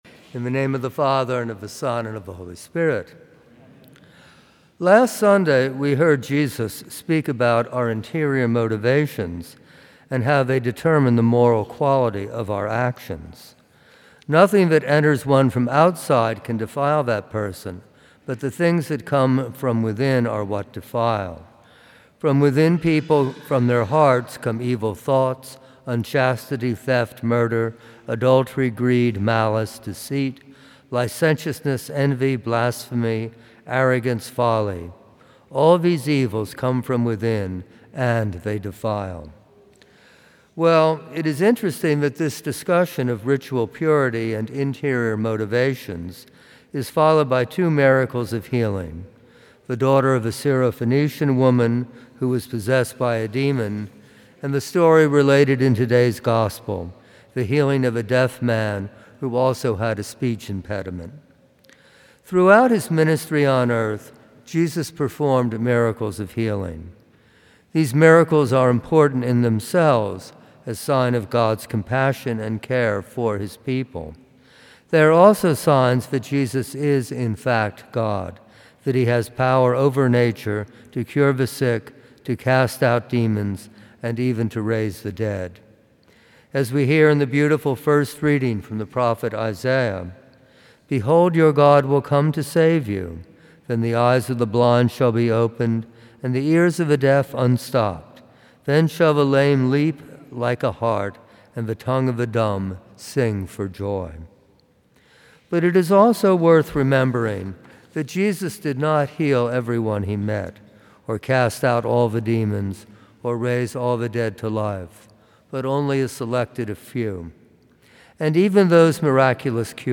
Homily
Homilies that are not part of any particular series.